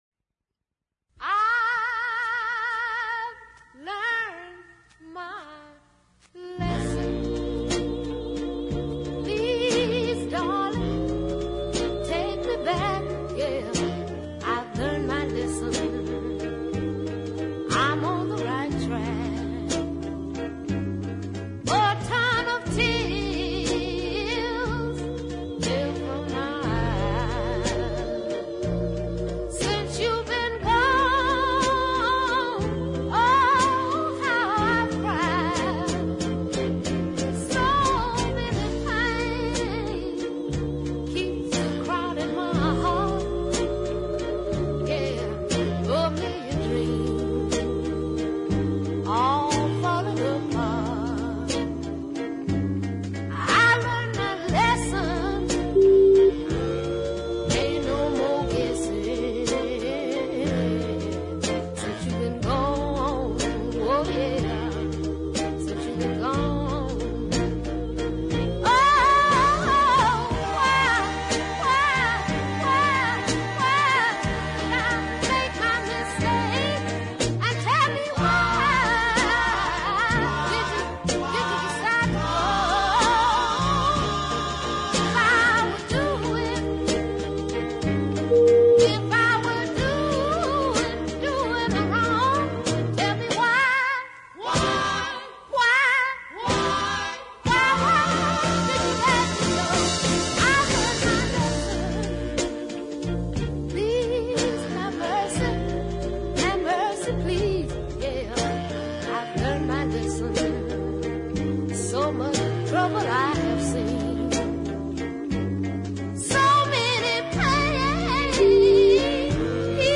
plaintive ballad